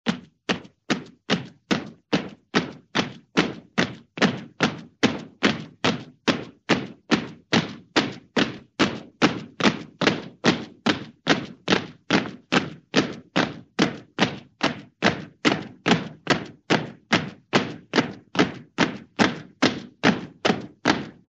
Солдаты шагают в строю nБойцы идут четким шагом nВоенные маршируют в ногу nСтрой солдат движется вперед nМарширующие воины идут